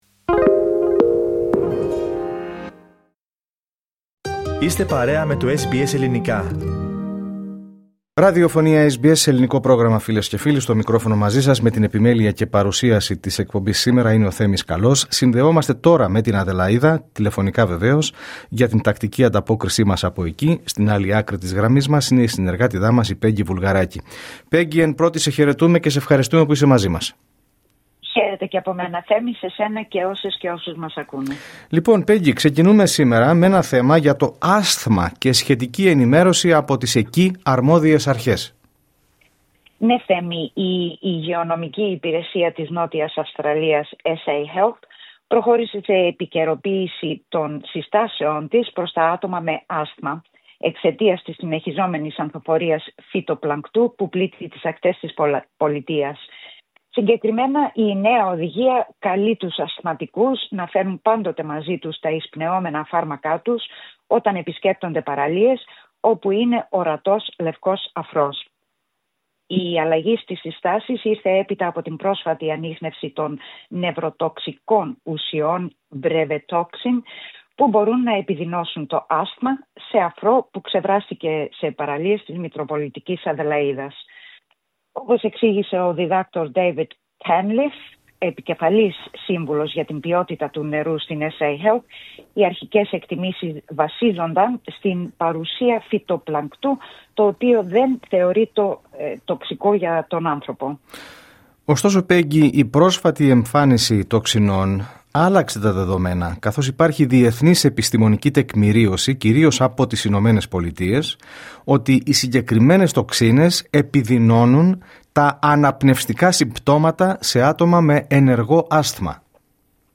Ακούστε την εβδομαδιαία ανταπόκριση από την Αδελαΐδα